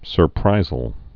(sər-prīzəl)